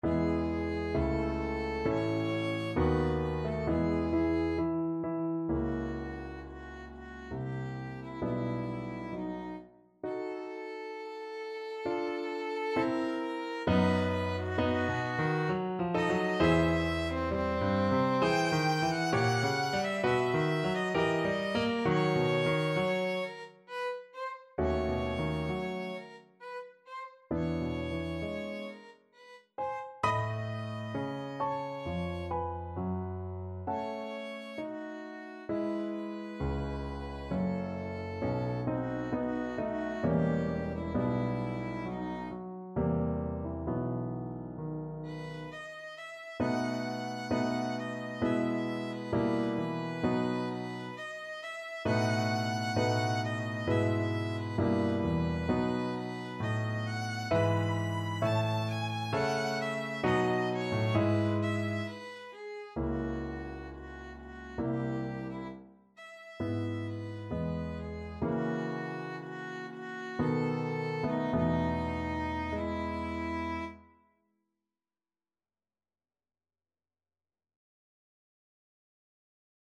Violin
E major (Sounding Pitch) (View more E major Music for Violin )
=66 Andante sostenuto
C5-B6
3/4 (View more 3/4 Music)
Classical (View more Classical Violin Music)